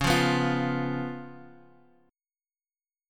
Db7 Chord
Listen to Db7 strummed